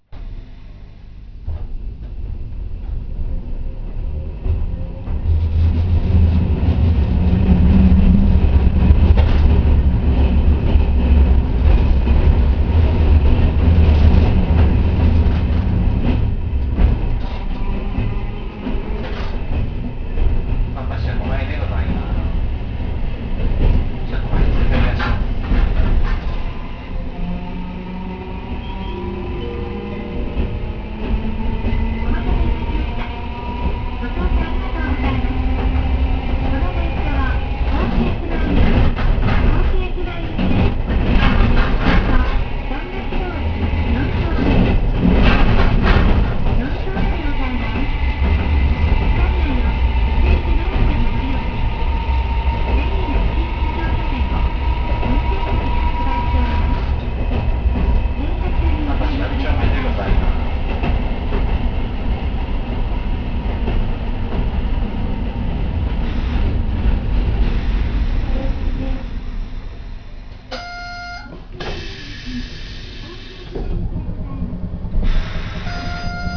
見た目は新しくても動き出せば豪快な音がするので、なんだか違和感。
・2000形走行音
【桟橋線】(桟橋車庫前手前の信号)→桟橋通四丁目（1分16秒：416KB）
見た目こそきれいなものの機器流用車であるため、走行音は今まで通り。静粛性は期待できません。